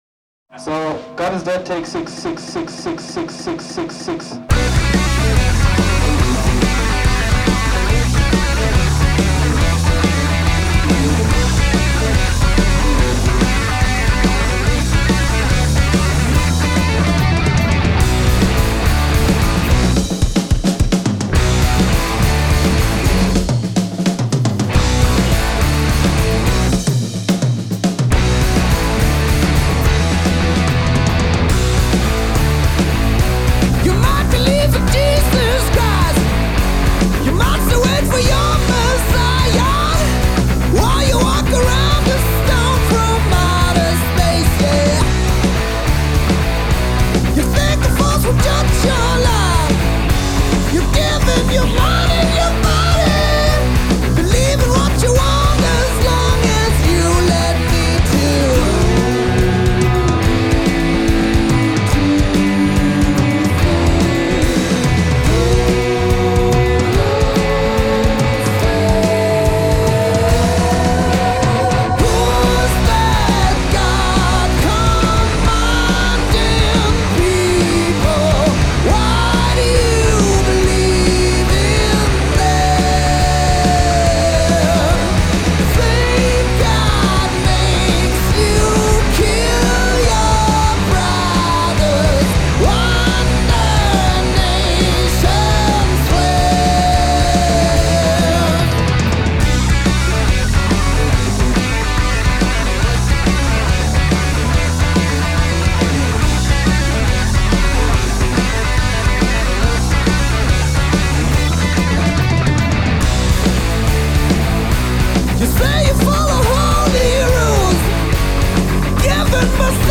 German heavy metal band